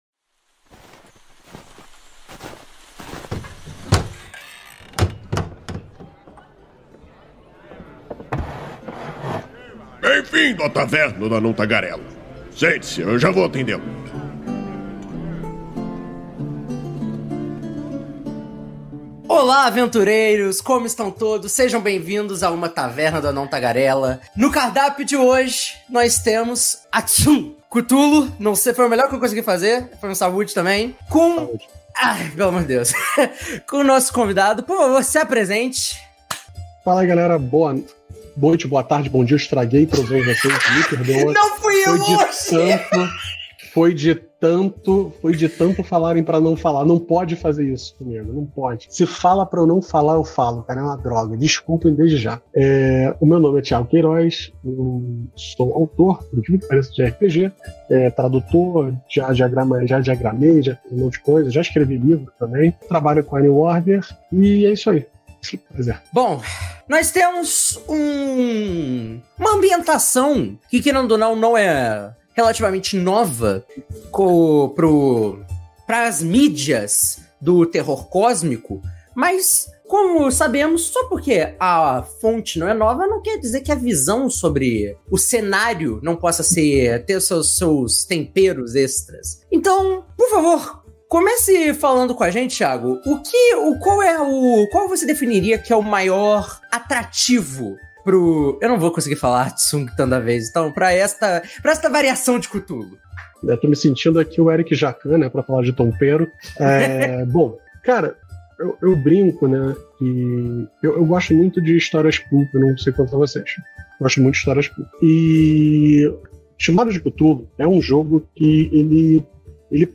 Venha conhecer esse sistema que traz o terror cósmico de Lovecraft com ênfase na ação, saiba como são criados os personagens e quais são as possibilidades de histórias nesse incrível cenário. A Taverna do Anão Tagarela é uma iniciativa do site Movimento RPG, que vai ao ar ao vivo na Twitch toda a segunda-feira e posteriormente é convertida em Podcast.